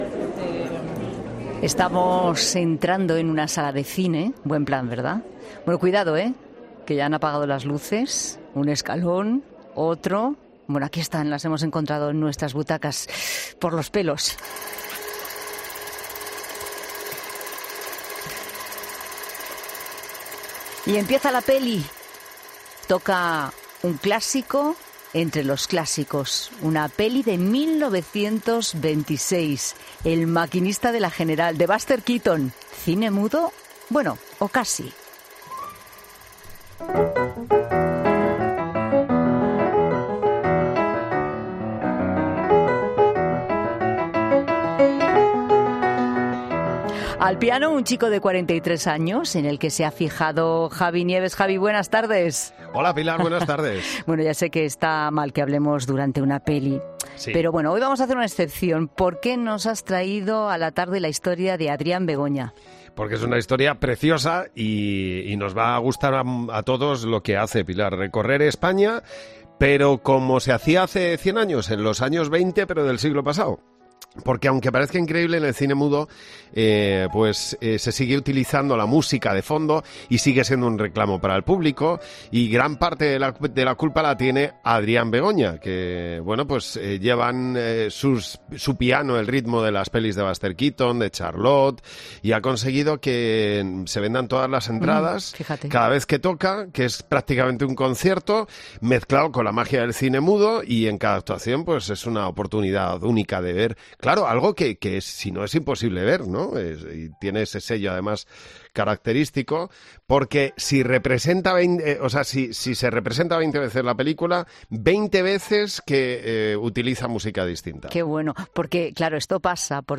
AUDIO: En 'La Tarde' hemos hablado con el pianista, que junto a su piano recorre España y pone música a las películas clásicas de cine mudo